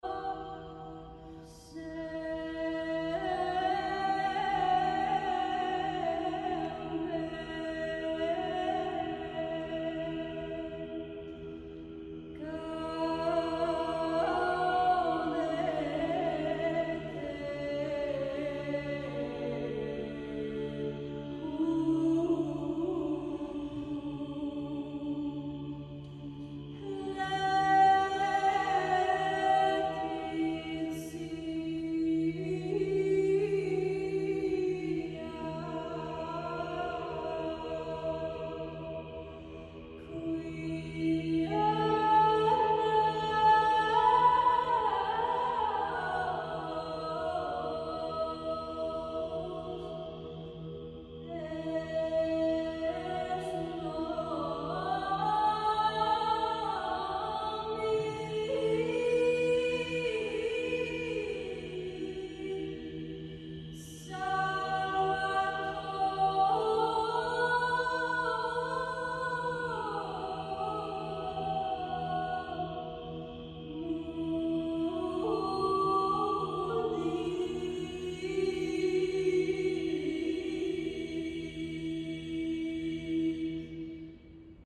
Upload By Idrîsî Ensemble
rehearsing a never recorded